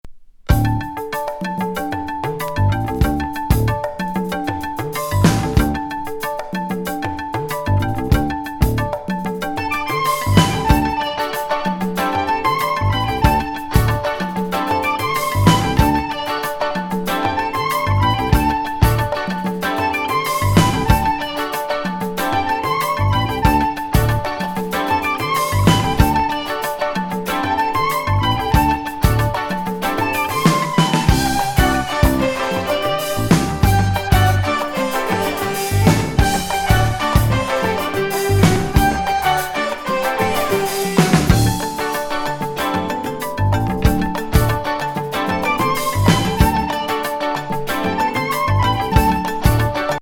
ニューエイジ・フュージョン